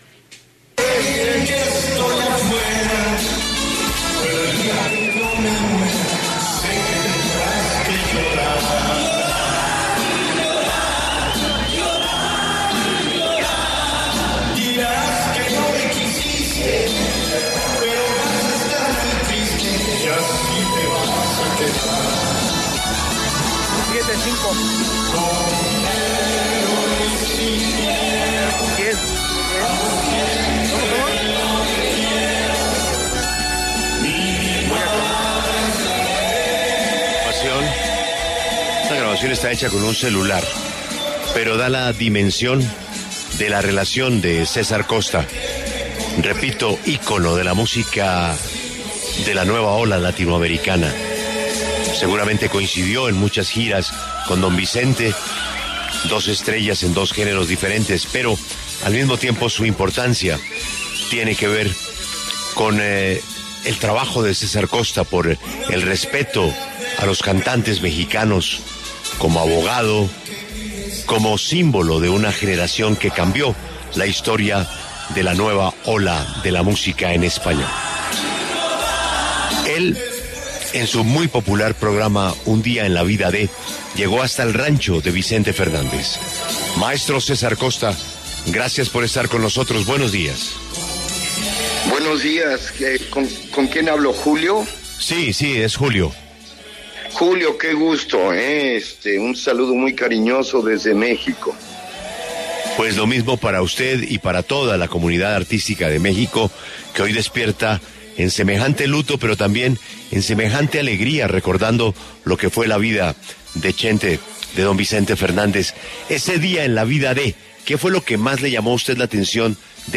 César Costa, cantante, productor, ícono del rock and roll y abogado mexicano, habló en La W sobre la entrevista que le hizo a Vicente Fernández en su rancho.
En el e4ncabezado escuche la entrevista completa con César Costa sobre la partida de Vicente Fernández.